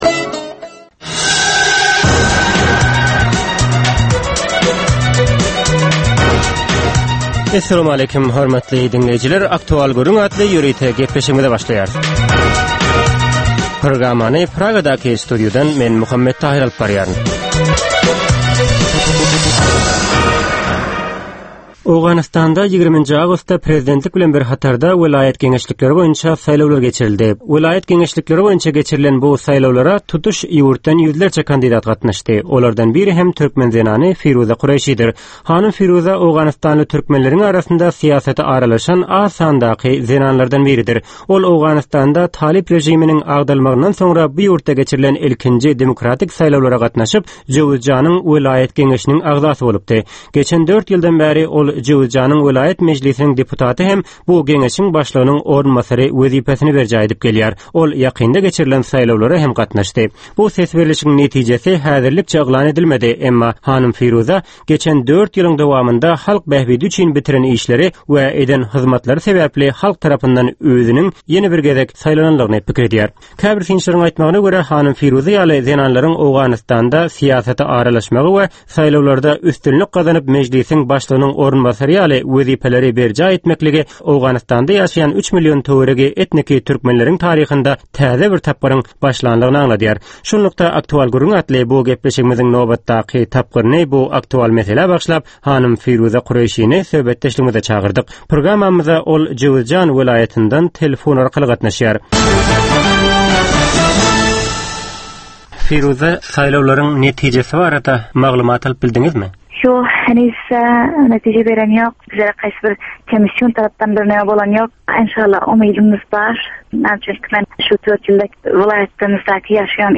Hepdänin dowamynda Türkmenistanda ýa-da halkara arenasynda ýüze çykan, bolup geçen möhüm wakalar, meseleler barada anyk bir bilermen ýa-da synçy bilen geçirilýän 10 minutlyk ýörite söhbetdeslik. Bu söhbetdeslikde anyk bir waka ýa-da mesele barada synçy ýa-da bilermen bilen aktual gürründeslik geçirilýär we meselänin dürli ugurlary barada pikir alsylýar.